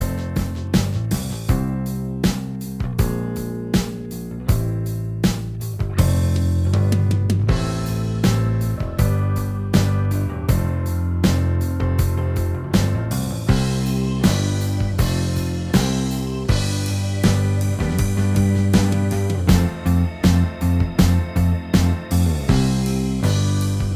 Minus Guitars Pop (1990s) 2:45 Buy £1.50